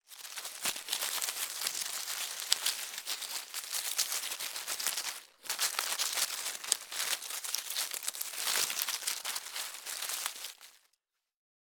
Звуки крота
Шорох крота в осенней листве